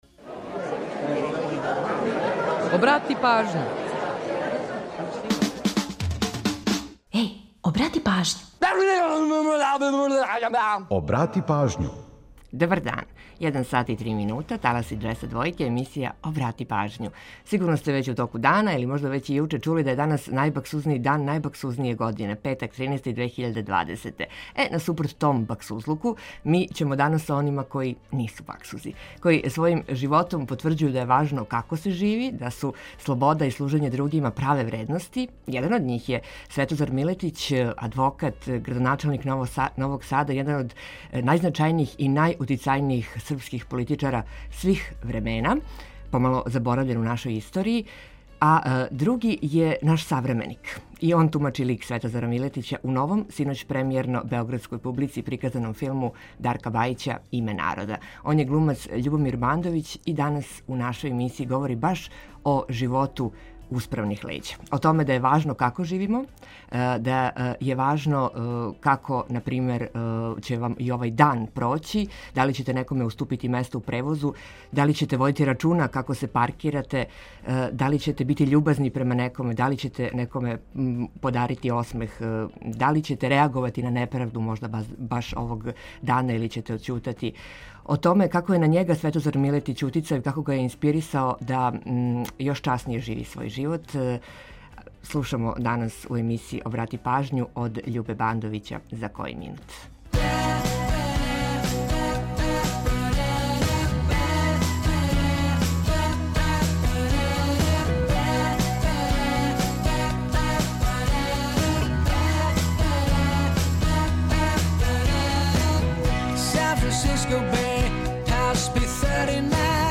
Интервју - Љубомир Бандовић, глумац